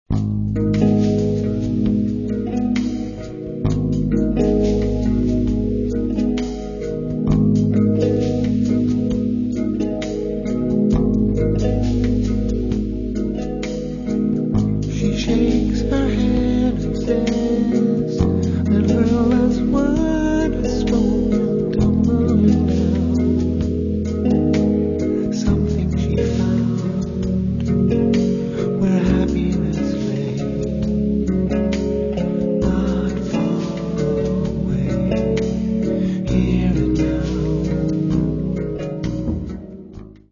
vocals, bass, keyboards and cello
guitars
Hammond B3 Organ
soprano saxophone
drums and percussion